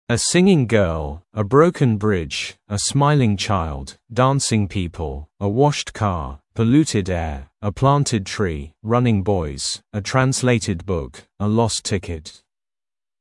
Произношение:
1. a singing girl – [э сингинг гё:л] – Поющая девочка
2. a broken bridge – [э броукэн бридж] – сломанный мост